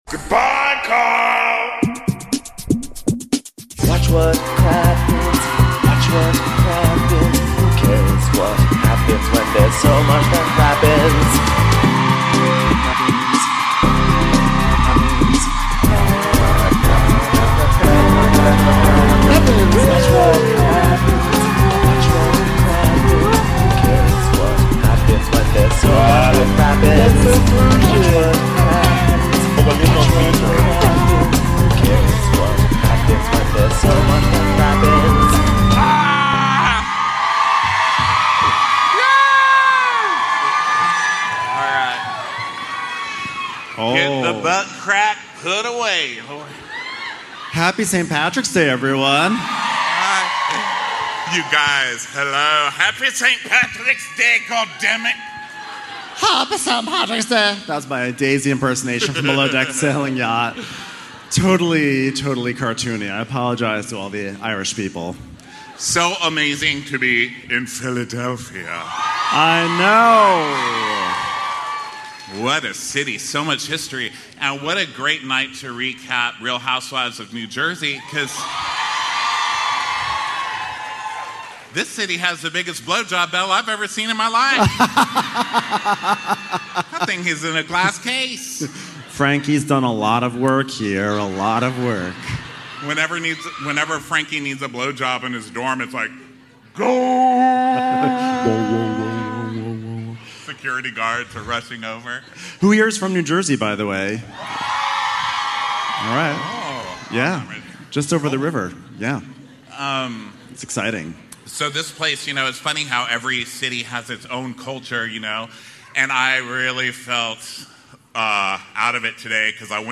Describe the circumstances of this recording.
RHONJ: Scrolling in the Deep - Live from Philly